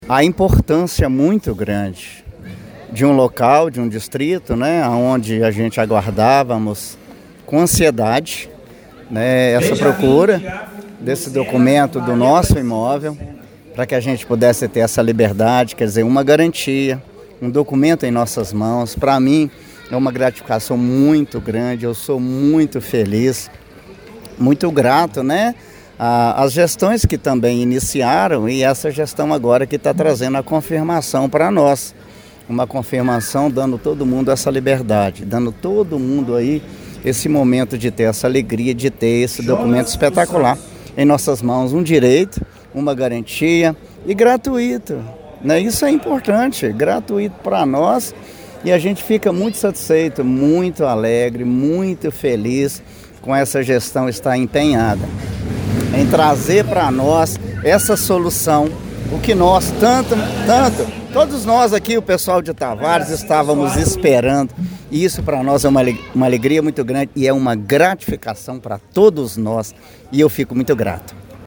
Relatos colhidos durante o evento traduzem o impacto social da medida: